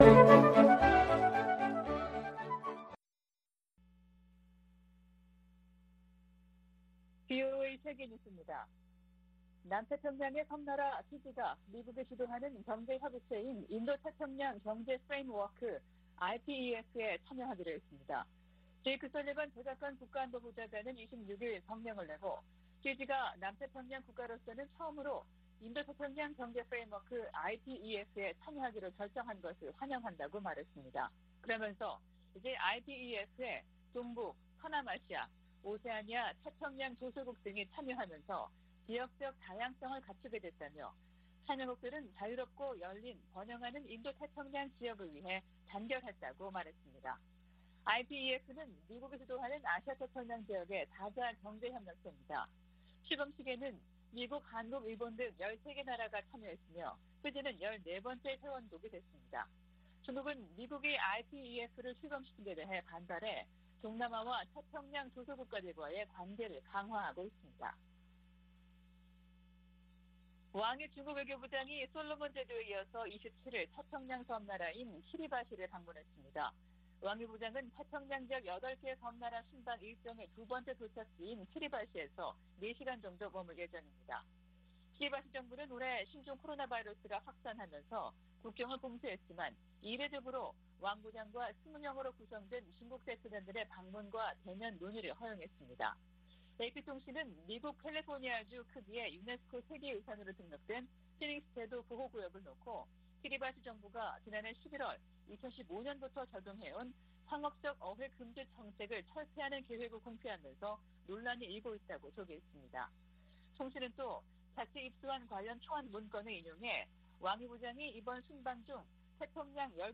VOA 한국어 아침 뉴스 프로그램 '워싱턴 뉴스 광장' 2022년 5월 28일 방송입니다. 유엔 안보리가 중국과 러시아의 반대로 새 대북 결의안 채택에 실패했습니다. 토니 블링컨 미 국무장관은 대중국 전략을 공개하면서 북한 핵 문제를 상호 ‘협력 분야’로 꼽았습니다. 미 국무부가 올해 초 제재한 북한 국적자 등의 이름을 연방관보에 게시했습니다.